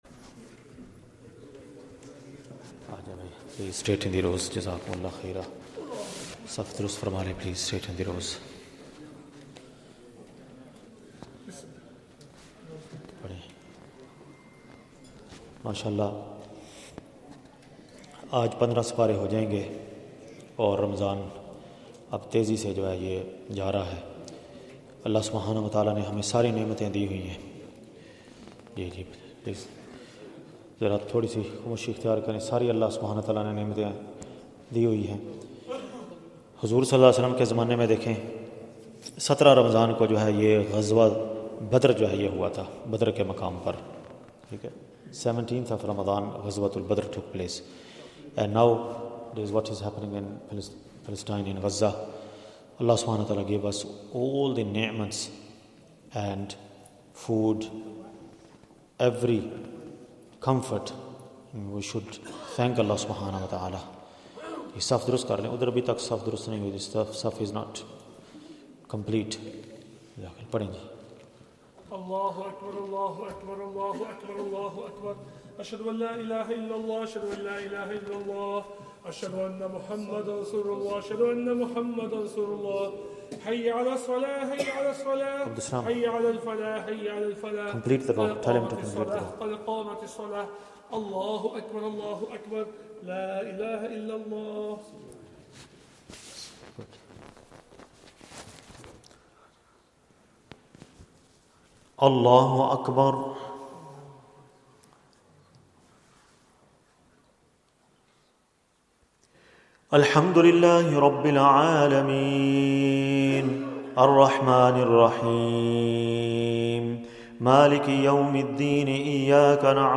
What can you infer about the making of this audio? Zakariyya Masjid Motherwell | Taraweeh | eMasjid Live